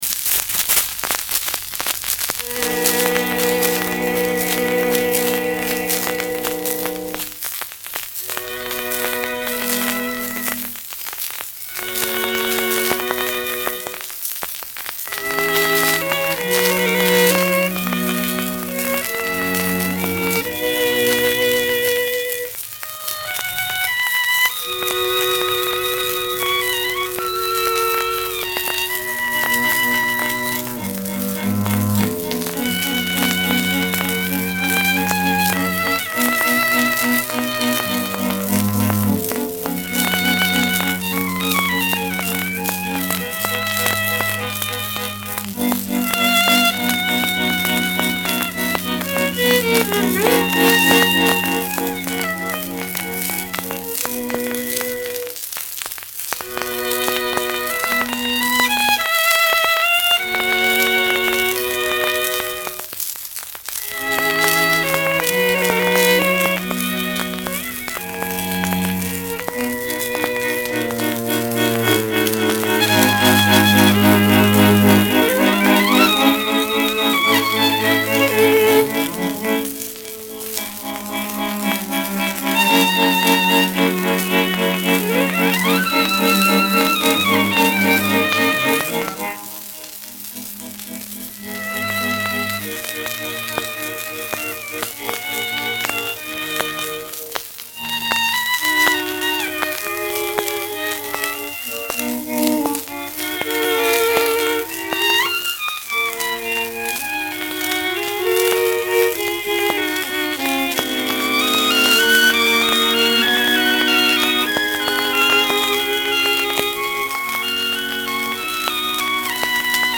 Cuartetos, cuerda, n. 1, re menor. Adagio
1 disco : 78 rpm ; 30 cm.
En etiqueta: Cuarteto instrumental
Int.: por el Cuarteto Rafael.
Grab.: Madrid, 1929-03-29 (The Gramophone Co. Discography)